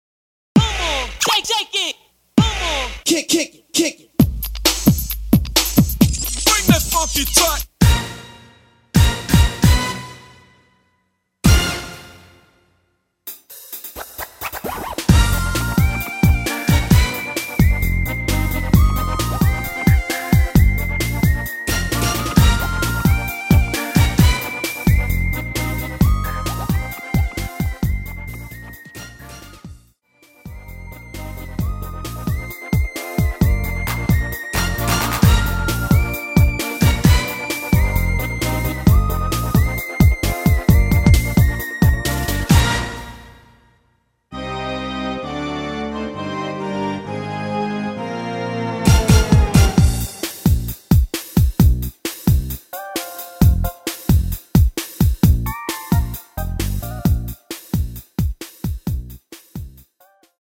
MR 간주와 후주가 너무 길어서 라이브에 사용하시기 좋게 짧게 편곡 하였습니다.
키 Bb 가수